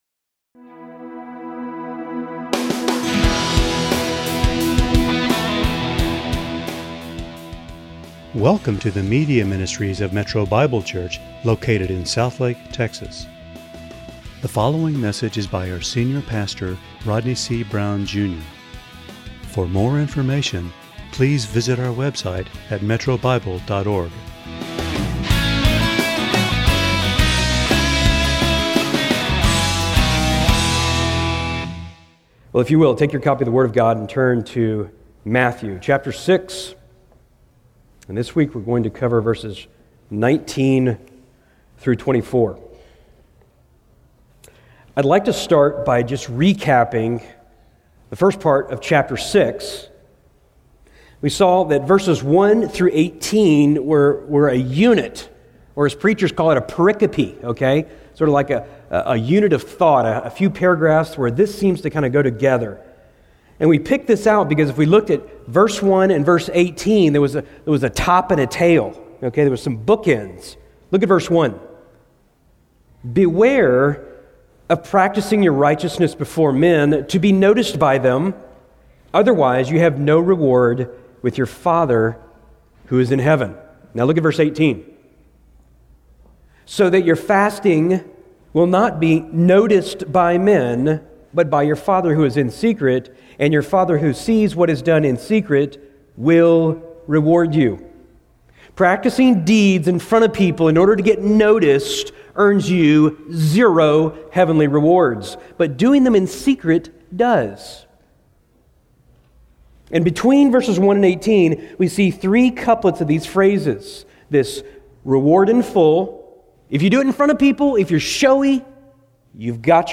× Home About sermons Give Menu All Messages All Sermons By Book By Type By Series By Year By Book Undivided Loyalty Believers' chief pursuit is Christ as their treasure.